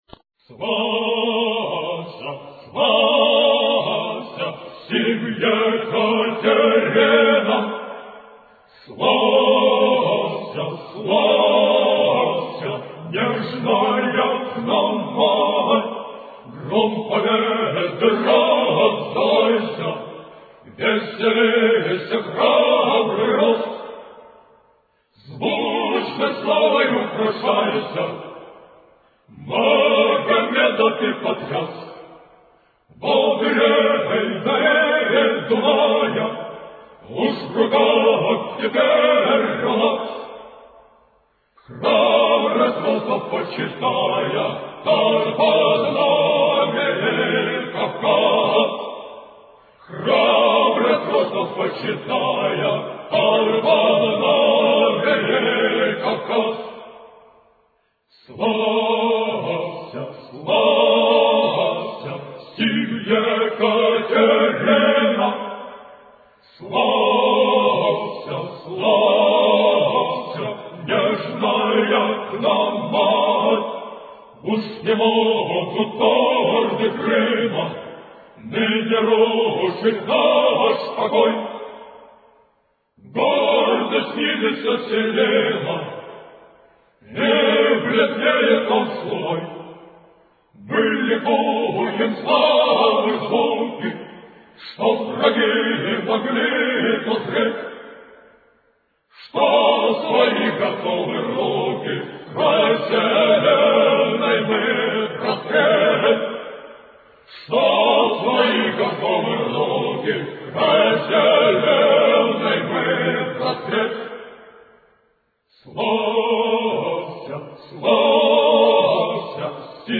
Вот как звучит гимн-полонез Осипа Козловского в современном хоровом исполнении — пропущен лишь куплет про «стон Синая» (то есть, крепости Измаил):